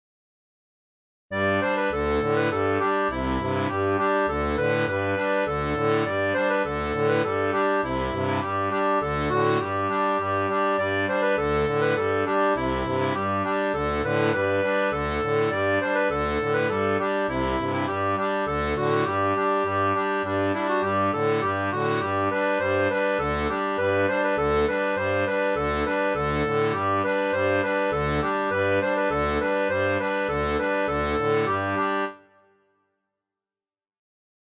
• Une tablature pour diato 2 rangs
Chant de marins